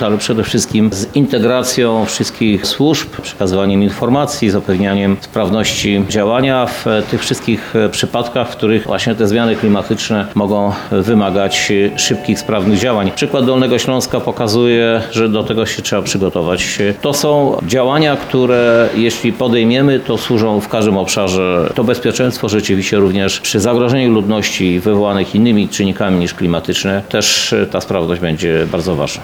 – Projekt przewiduje budowę mobilnego stanowiska kierowania z wyposażeniem w specjalistyczny sprzęt – mówi prezydent miasta Lublin Krzysztof Żuk.